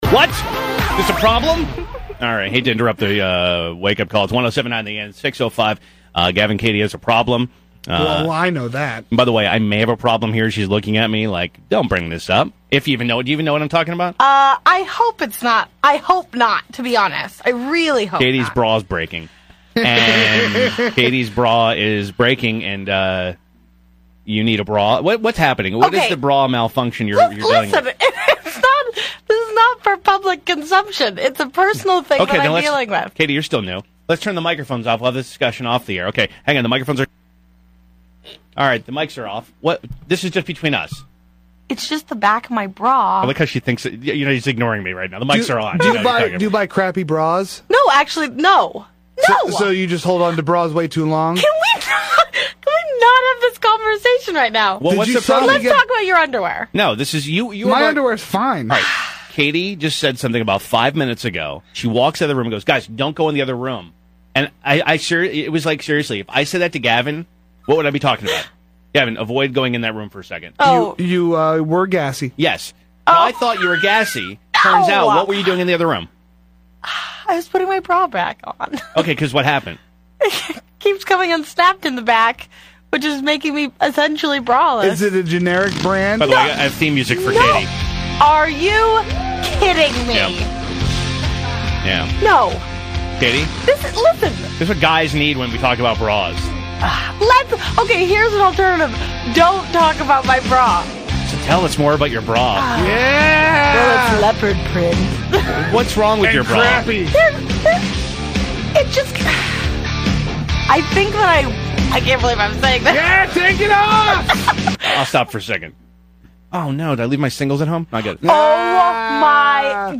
Airchecks